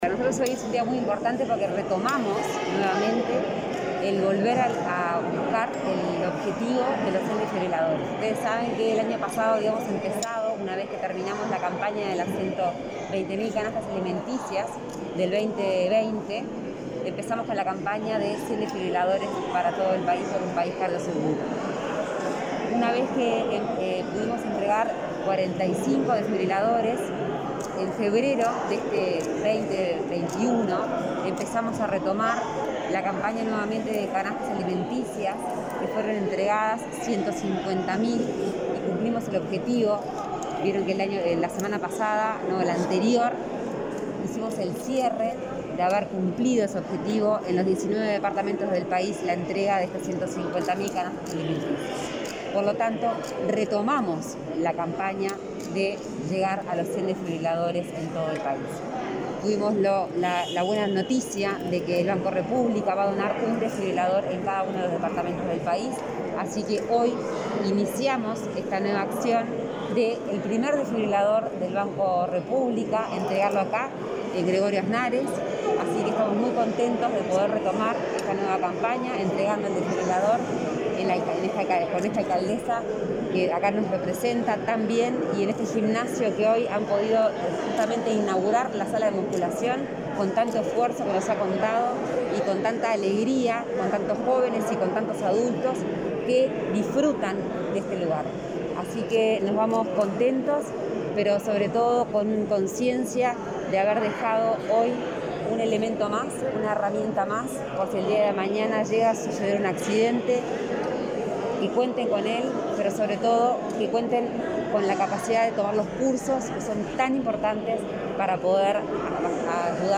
Declaraciones a la prensa de Lorena Ponce de León
Declaraciones a la prensa de Lorena Ponce de León 02/09/2021 Compartir Facebook X Copiar enlace WhatsApp LinkedIn El Banco de la República (BROU) entregó, este viernes 3 en Gregorio Aznárez, Maldonado, el primer desfibrilador, de un total de 19 que donó a la Secretaría Nacional del Deporte, a través de la iniciativa Unidos para Ayudar. En ese marco, la impulsora de este grupo solidario dialogó con la prensa.